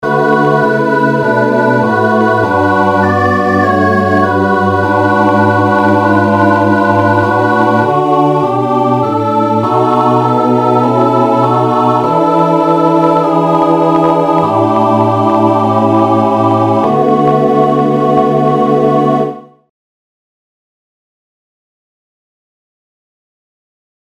corrige realisation a 4 voix 20.mp3